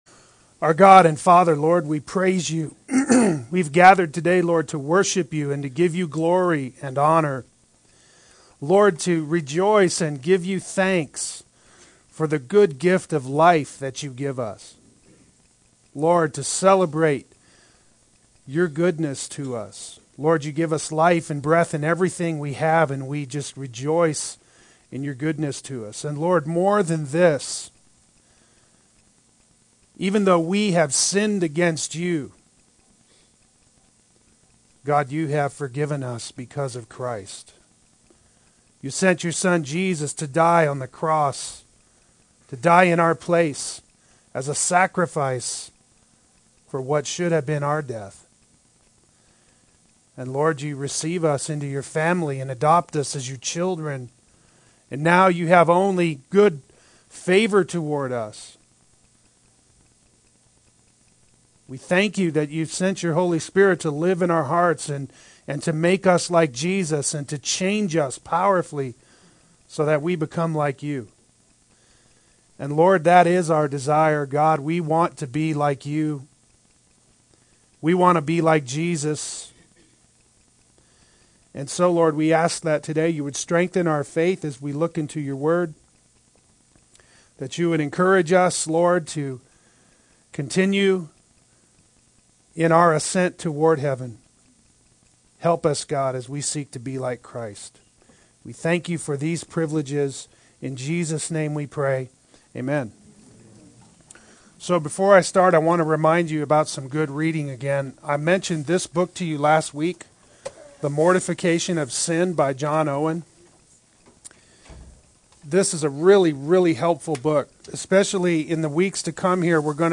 Sanctification and Our Daily Failures Adult Sunday School